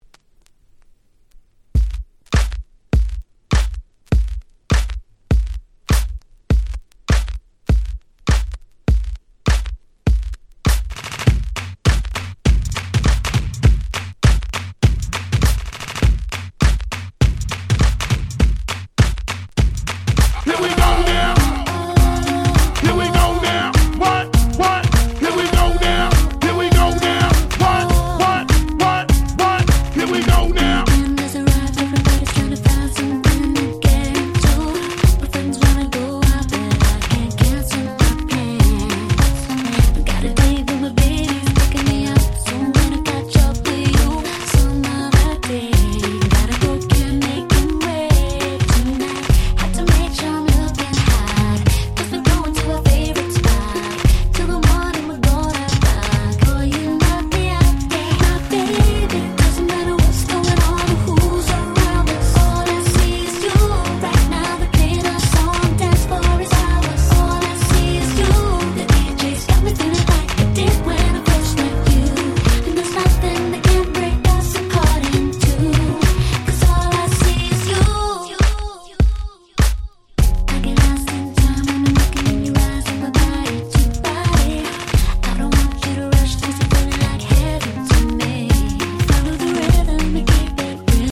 07' Super Nice R&B !!
キラキラでめちゃ良い曲！！